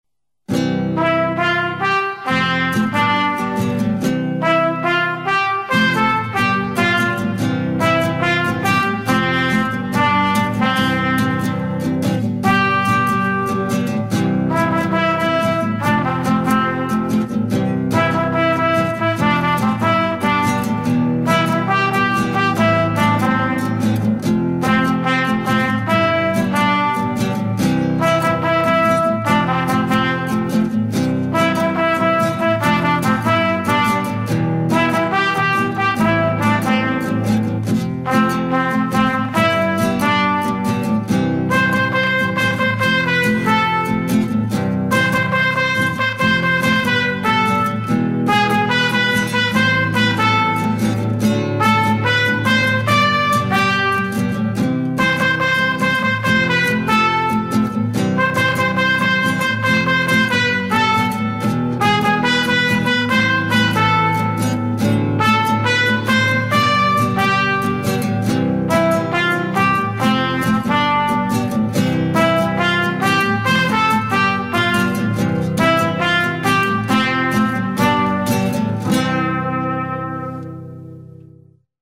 Traditional Songs
Zyczeniadlanaszejwsi-Instrumentalversion.mp3